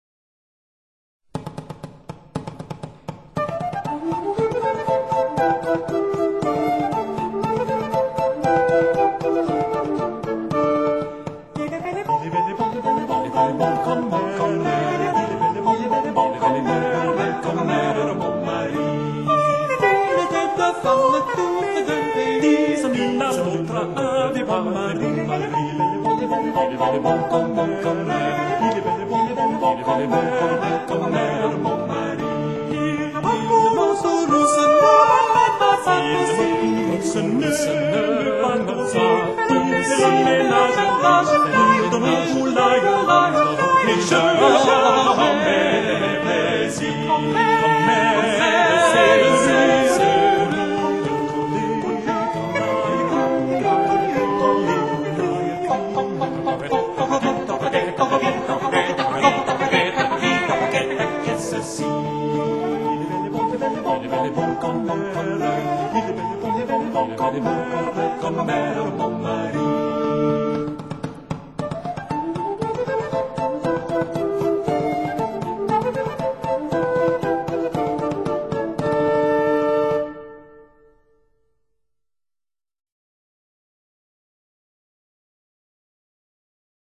欣赏：  法国牧歌《我有一个好丈夫》